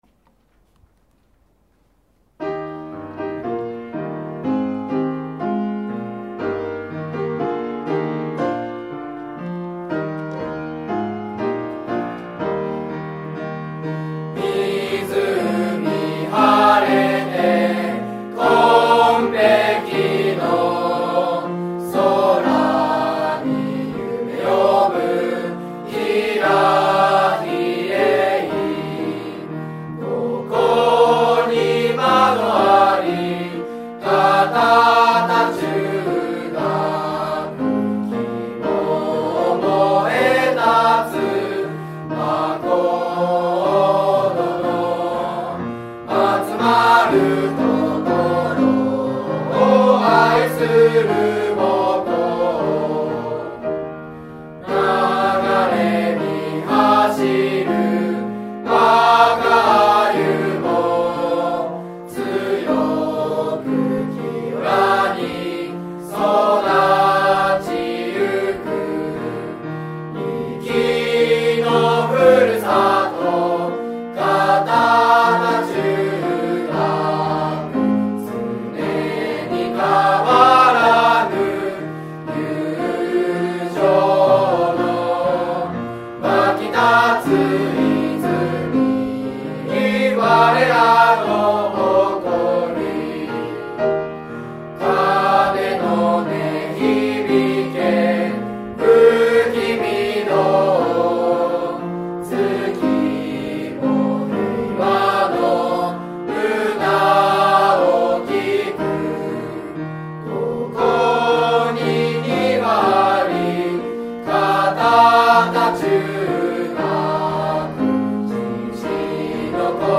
校歌
在校生による校歌の録音データ（mp3形式）
混声